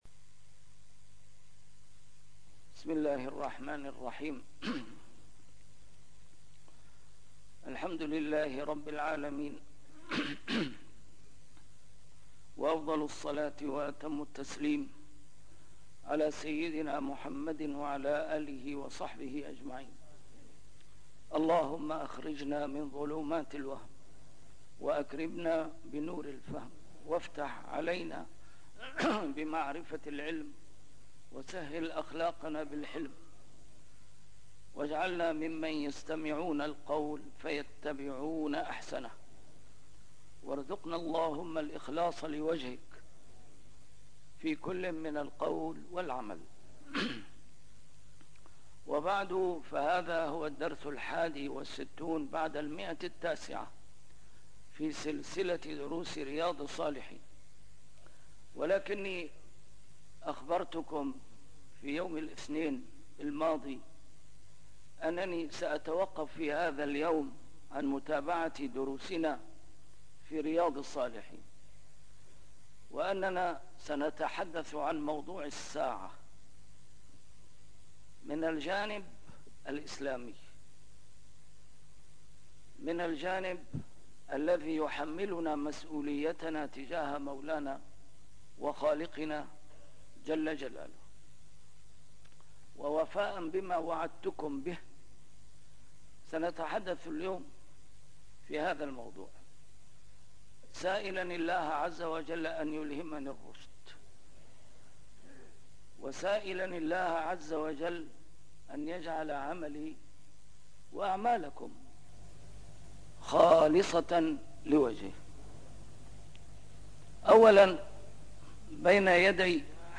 A MARTYR SCHOLAR: IMAM MUHAMMAD SAEED RAMADAN AL-BOUTI - الدروس العلمية - شرح كتاب رياض الصالحين - 961- شرح رياض الصالحين: موضوع الساعة والهجوم على سوريا وسببه البعيد